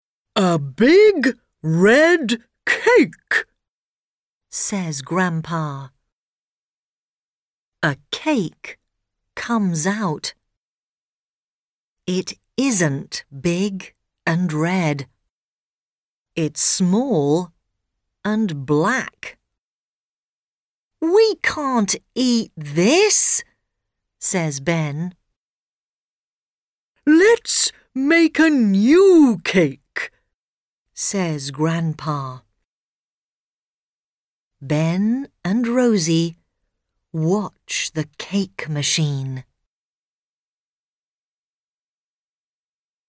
Track 3 The Cake Machine British English.mp3